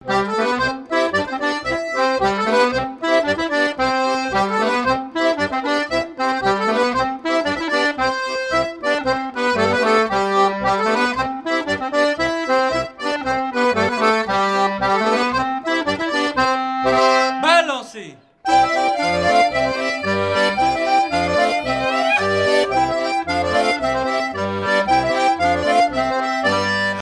danse : branle : avant-deux
Pièce musicale éditée